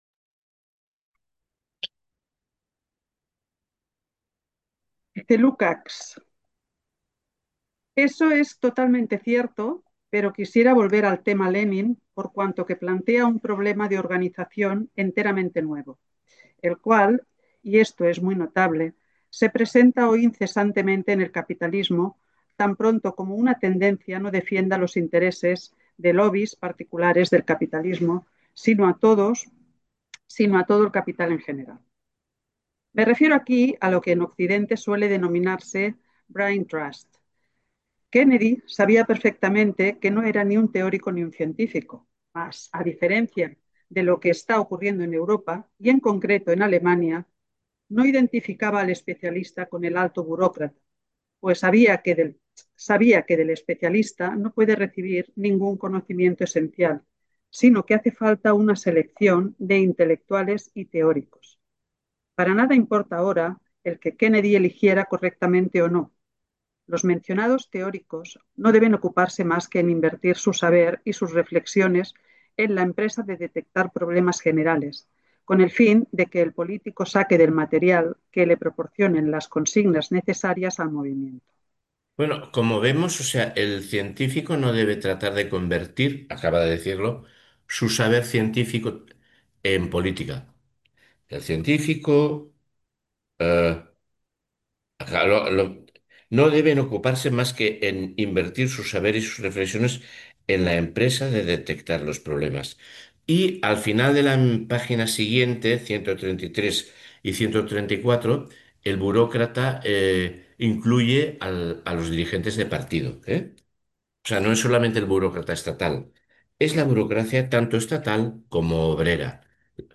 La forma de proceder es leer anticipadamente unas 20 o 25 páginas de texto, que posteriormente son releídas y comentadas en una puesta en común, que dirige un monitor.
En cualquier caso, la grabación del seminario será publicada posteriormente en la web de Espaimarx.